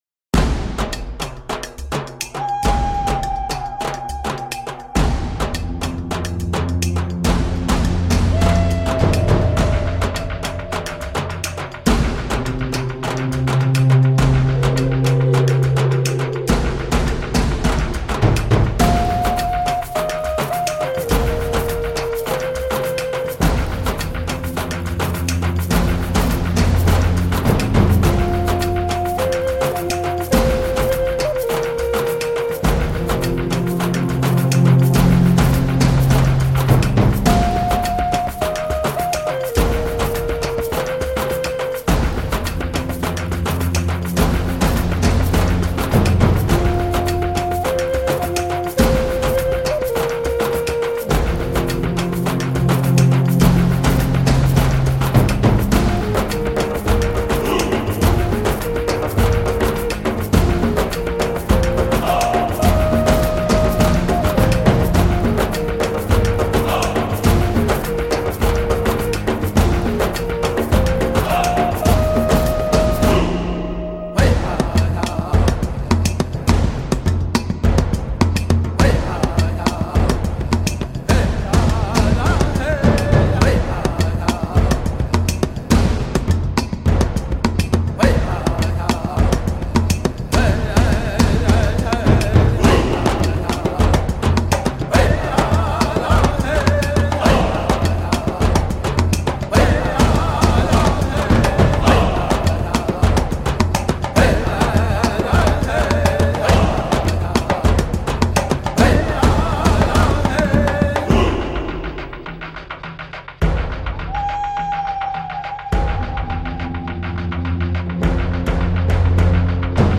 BGM鼓点激昂